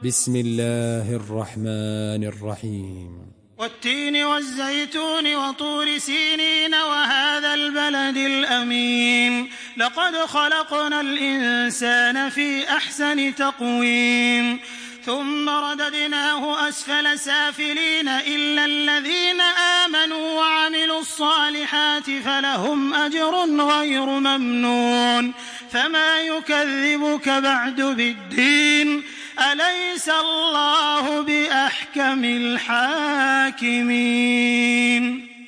تحميل سورة التين بصوت تراويح الحرم المكي 1426
مرتل حفص عن عاصم